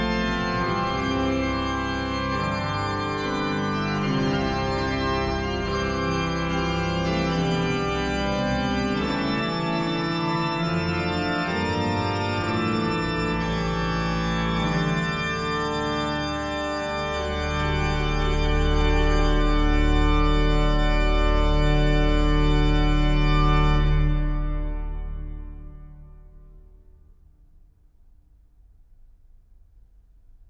"templateExpression" => "Musique classique"